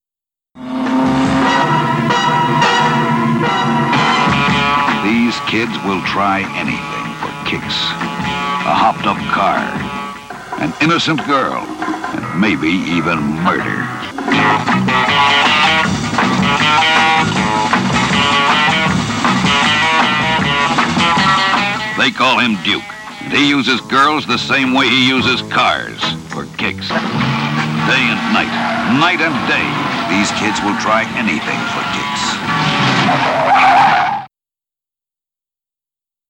90 Hot Rods to Hell (trailer)